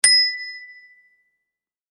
機械・乗り物 （94件）
自転車ベル2.mp3